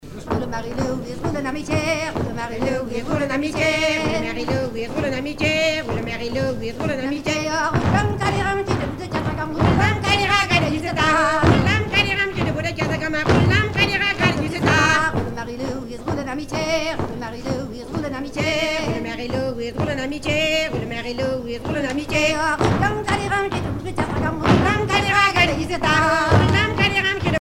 danse : passepied
Pièce musicale éditée